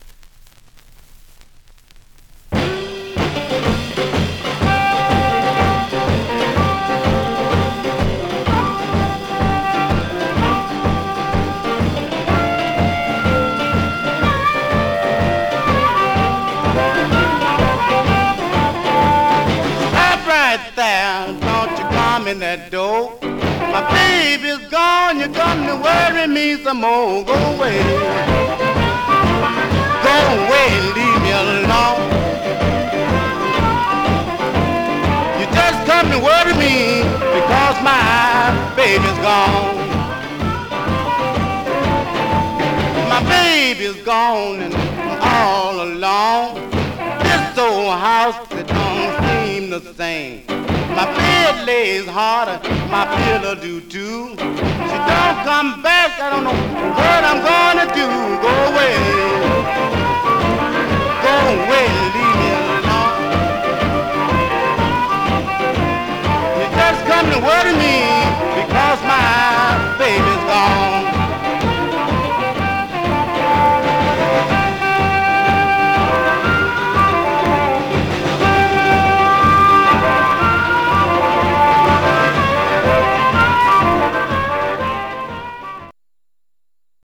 Surface noise/wear Stereo/mono Mono
Rythm and Blues Condition